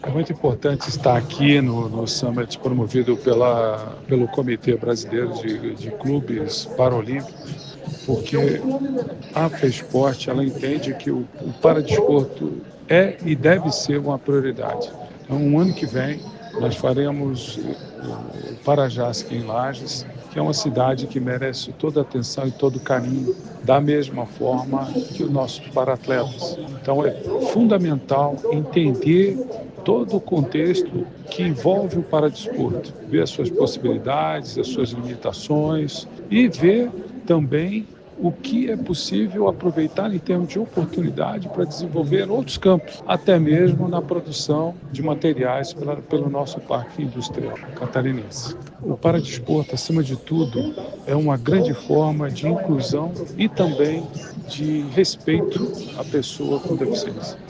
O presidente da Fesporte, Freibergue Nascimento, ressalta que o encontro serve para conhecer novas experiências e oportunidades para os paradesportistas catarinenses:
SECOM-Sonora-Presidente-Fesporte-Summit-Nacional-Paradesporto.mp3